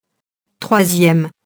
troisième [trwazjɛm]